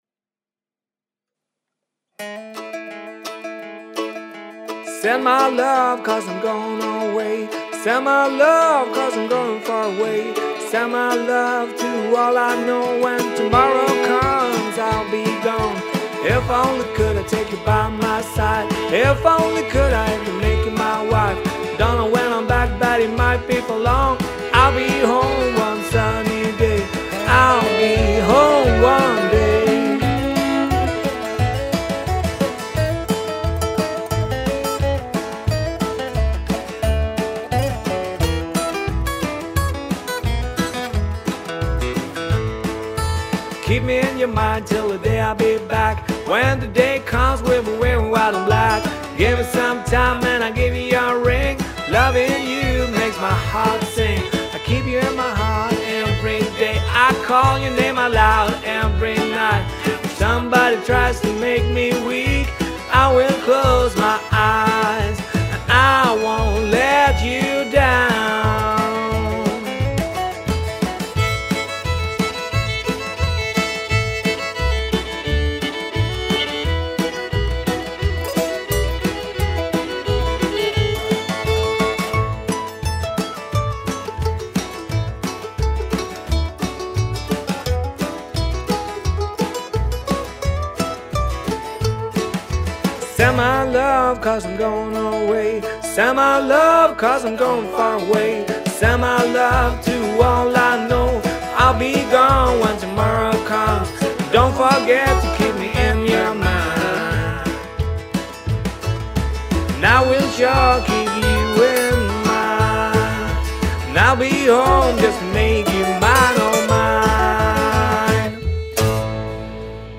I've recorded the drums, the upright and one of the acoustic guitars live, from then on worked with tracking.